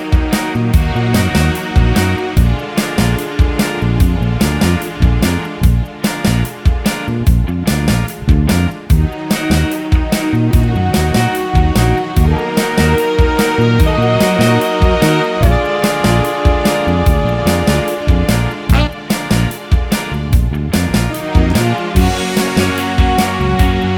no Backing Vocals Pop (2000s) 3:29 Buy £1.50